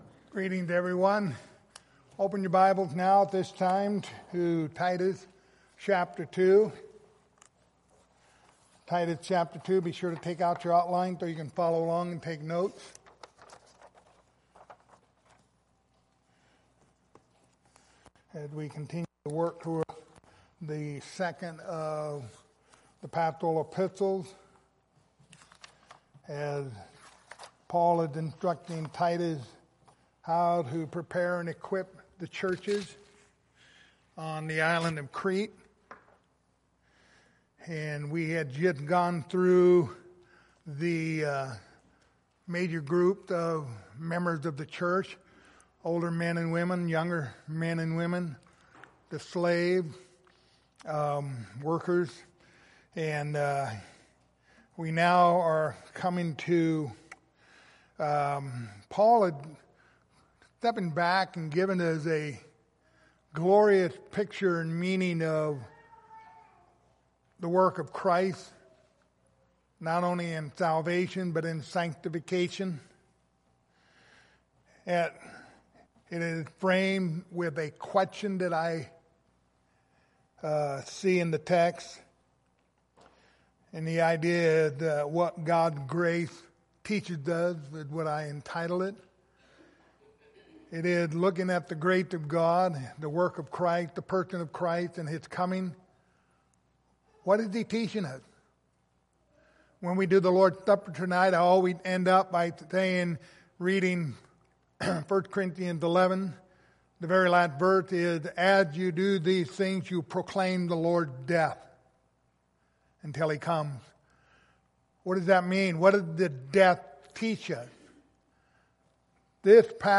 Pastoral Epistles Passage: Titus 2:11-12 Service Type: Sunday Morning Topics